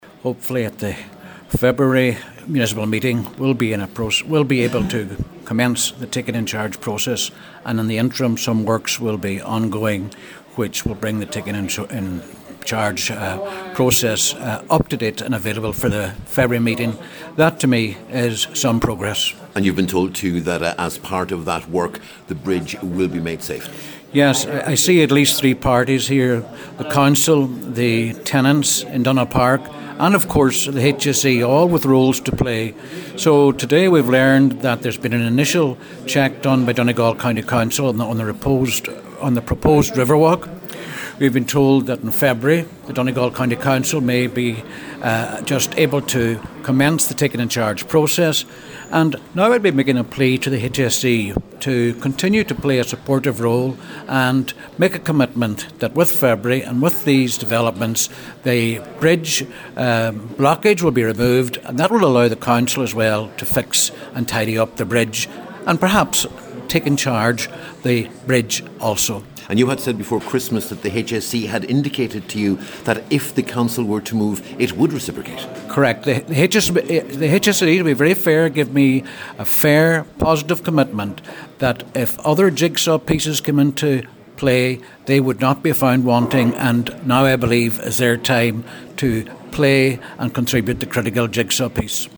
Cllr Albert Doherty says that will hopefully now happen: